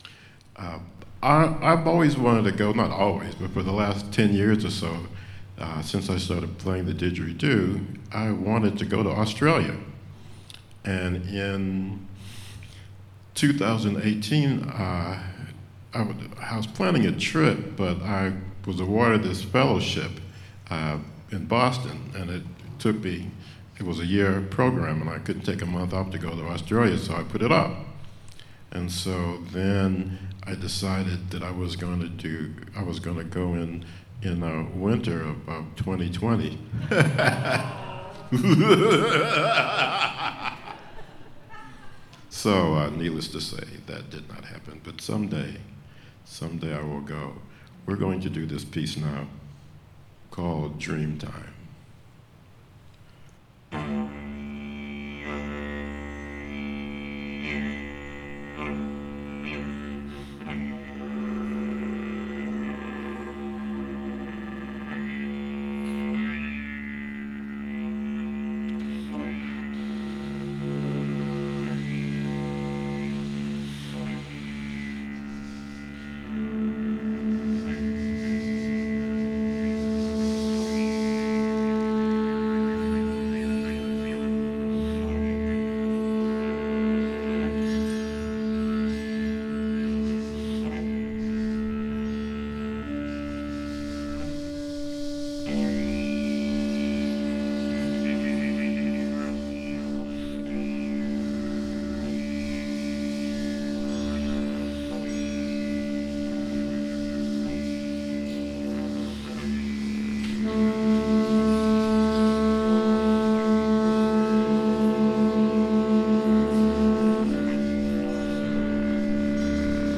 Symphony of Crickets